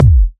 Chart Kick 04.wav